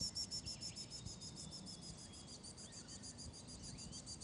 For example, this is a superb fairy-wren responding to a shining bronze-cuckoo in Australia.
Superb fairy-wren responding to a shining bronze-cuckoo.